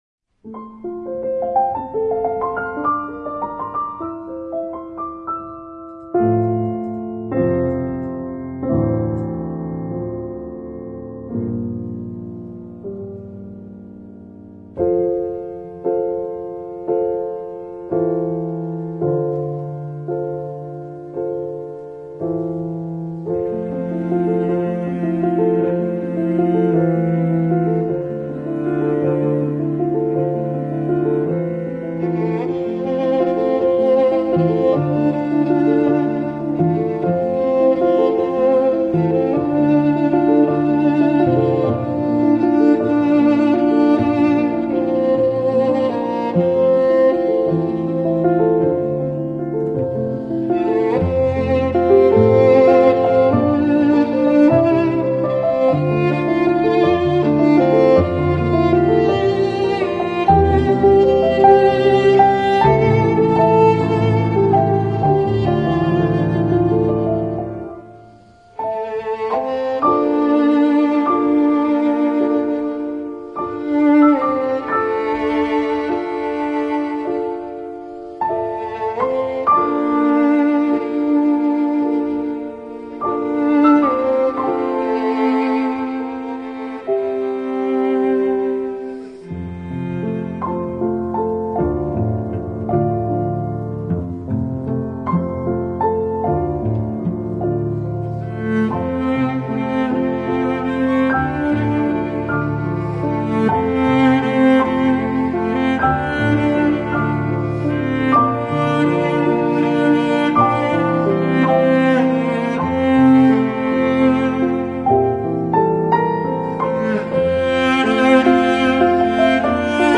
Piano, Violin, Cello